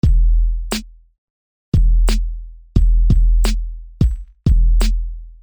Tag: 88 bpm Hip Hop Loops Drum Loops 939.72 KB wav Key : Unknown